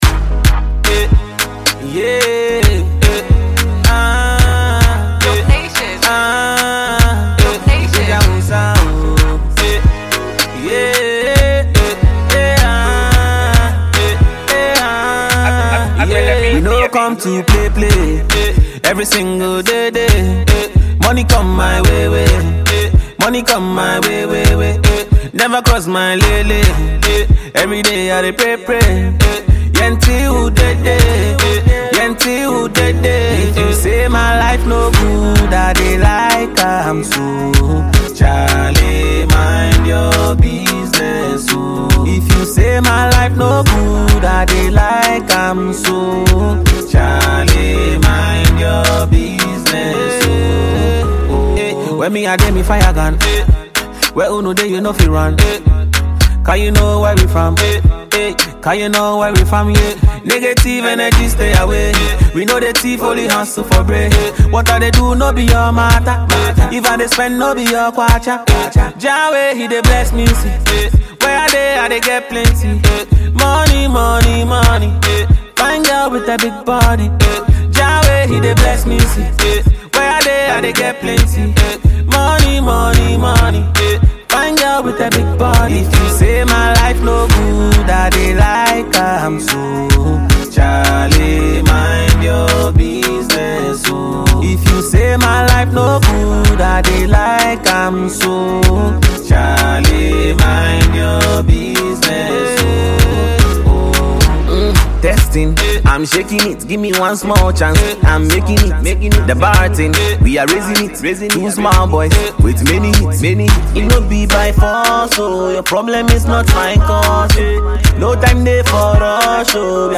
Ghana Music Music
Ghanaian music duo